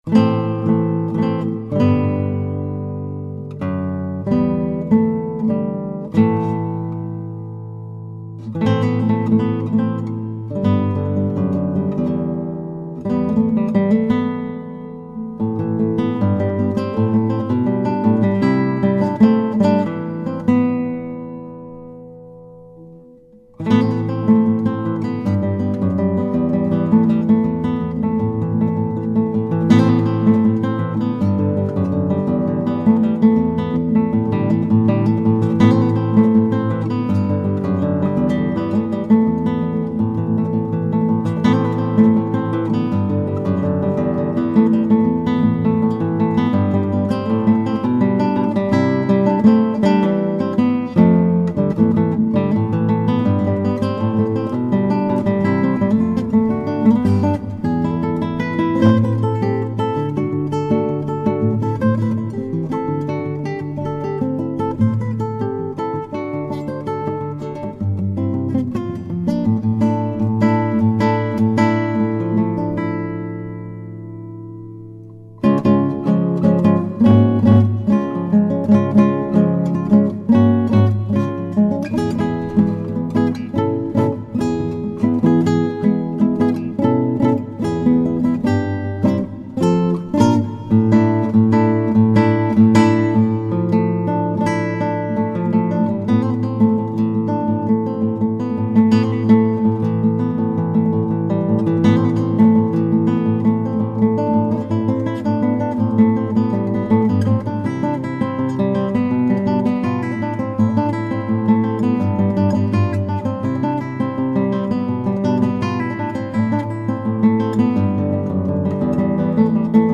Solo Guitar Demos – Click Here
SOLOS